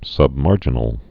(sŭb-märjə-nəl)